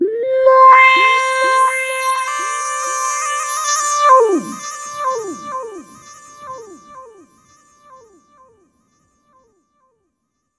VOCODE FX2-L.wav